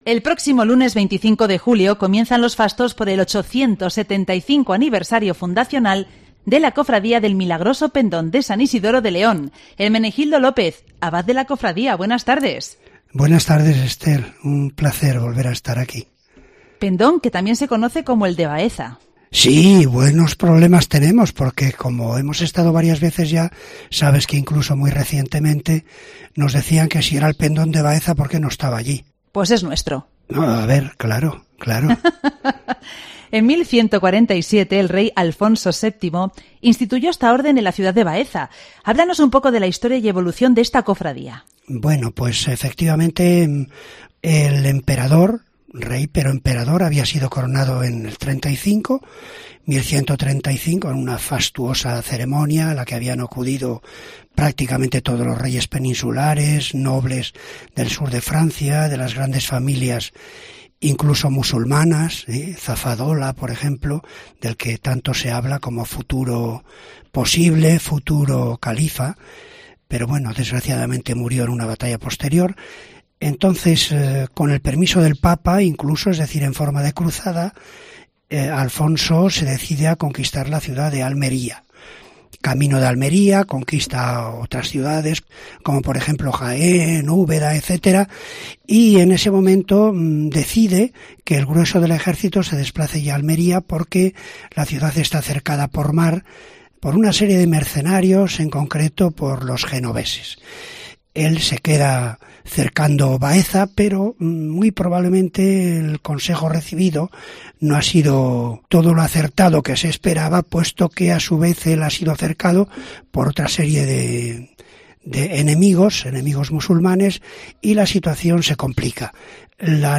León